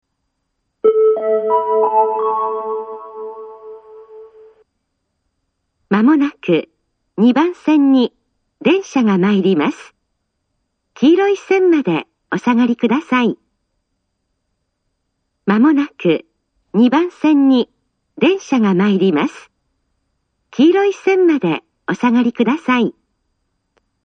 ２番線接近放送